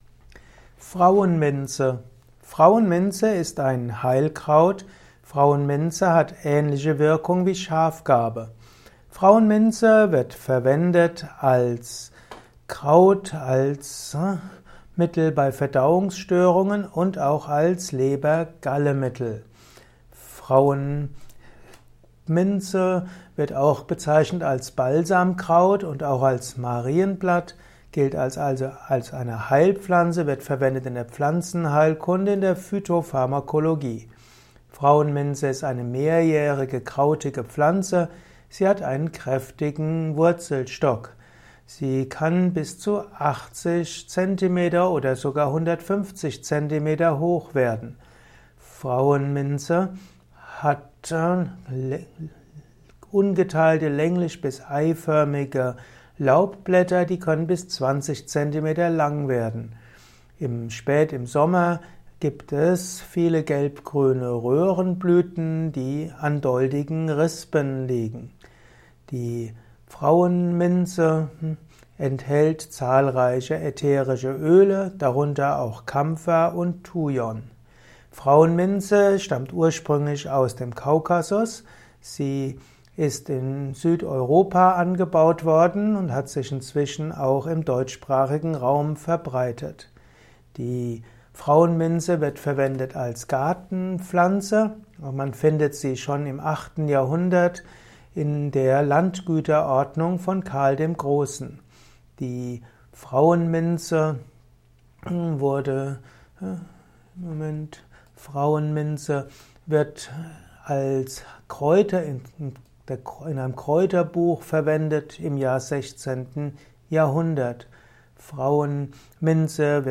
Ein Kurzvortrag über Frauenminze